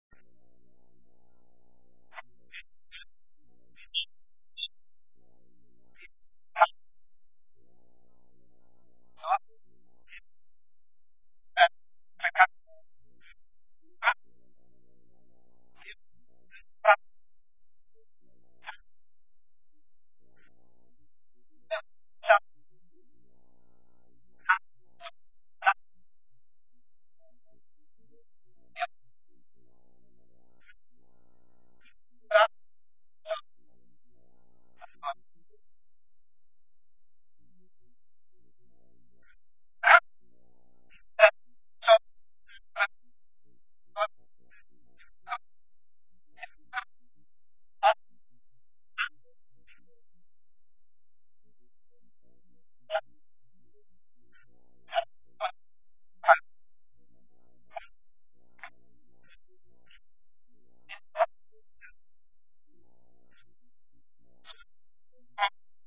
Vortrag 1: Ref. 1 / Teil 1 (leider nur wenige Minuten)